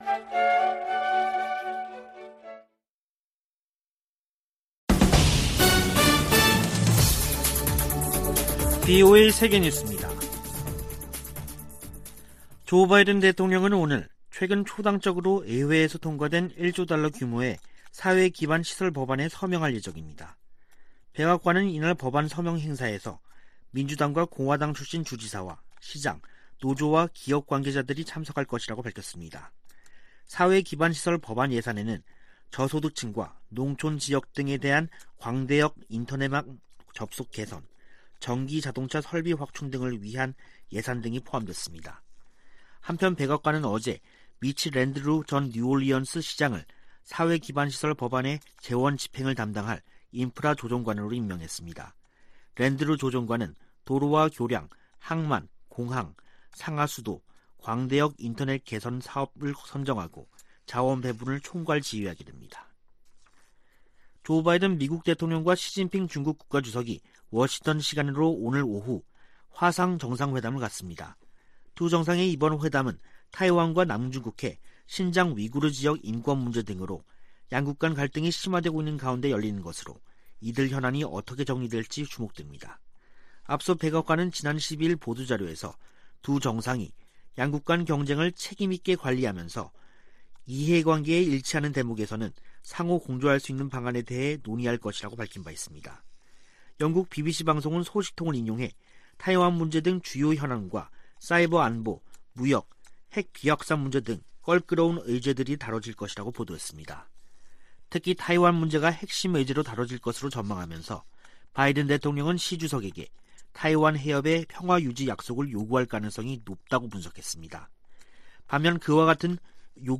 VOA 한국어 간판 뉴스 프로그램 '뉴스 투데이', 2021년 11월 15일 2부 방송입니다. 미국과 한국 사이에 종전선언 논의가 이어지는 가운데 북한이 유엔군사령부 해체를 연이어 주장하고 있습니다. 미 국무부 동아시아태평양 담당 차관보는 미국이 북한에 전제조건 없는 대화 제안을 했으며, 북한의 답을 기다리고 있다고 말했습니다.